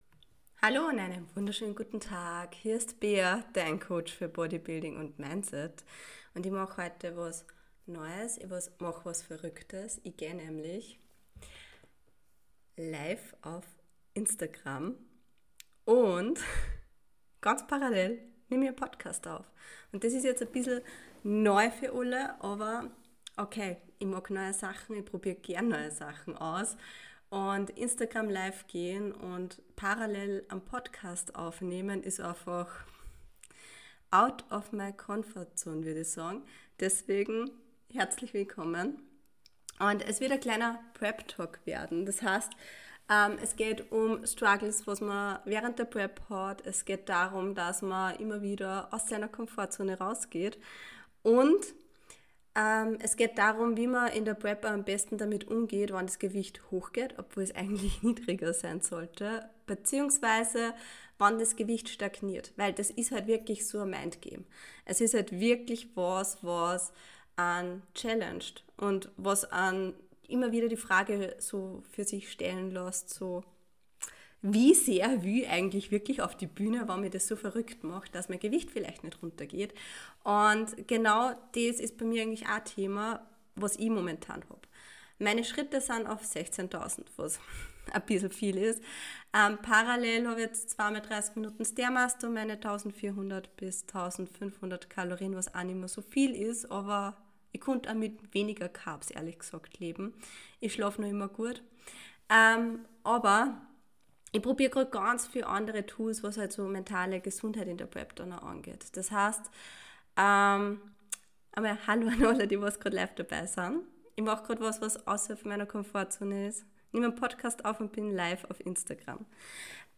#102 – IG-Live: Prep-Talk & Bonus für Dich ~ Strong Passion Podcast
Erstes IG-Live mit Podcast-Aufnahme!